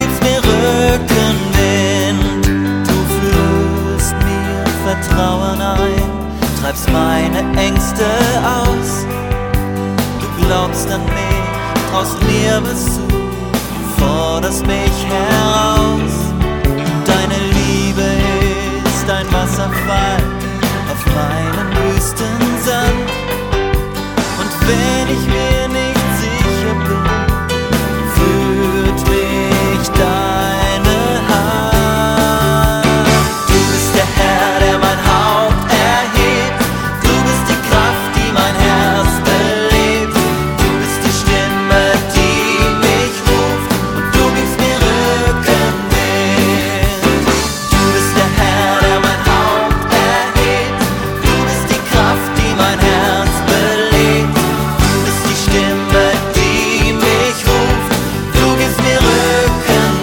Lieder für Momente der Anbetung
Lobpreis